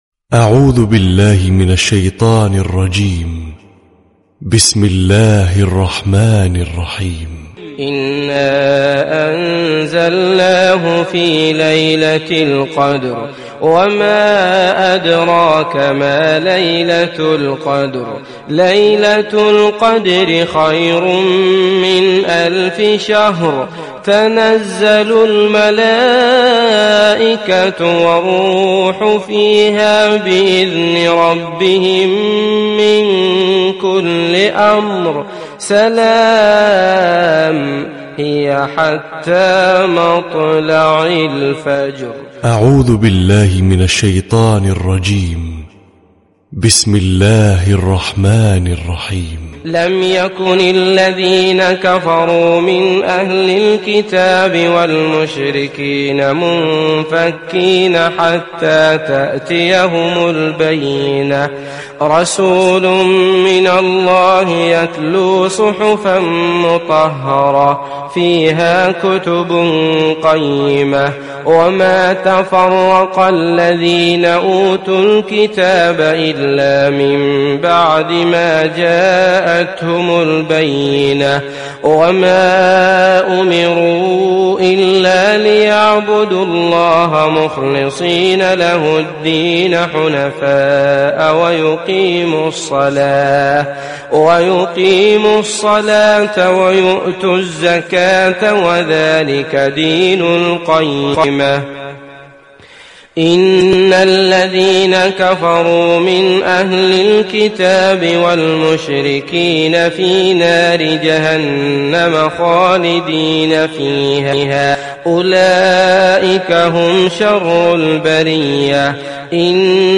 🕋🌻•تلاوة صباحية•🌻🕋
🎙 القارئ : عبدالله المطرود